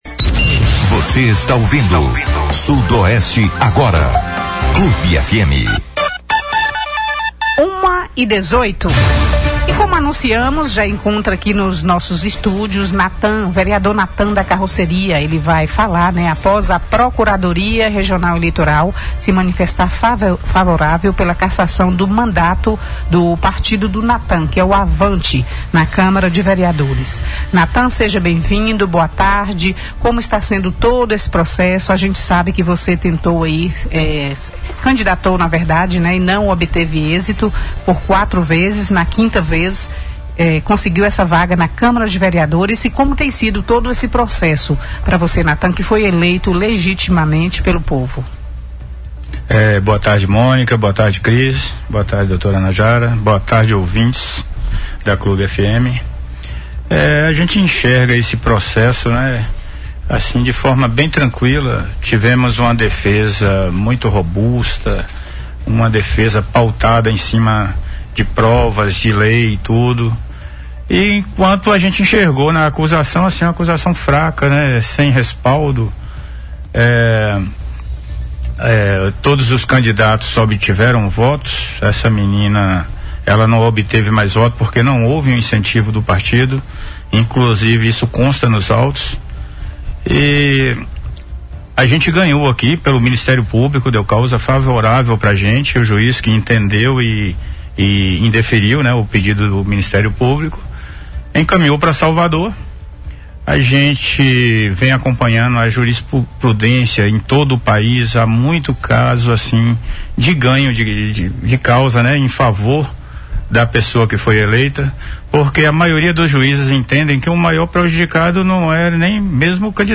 Entrevista à Rádio Clube de Conquista | Natan confiante na manutenção do seu mandato em Vitória da Conquista